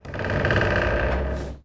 skrzypienie2.wav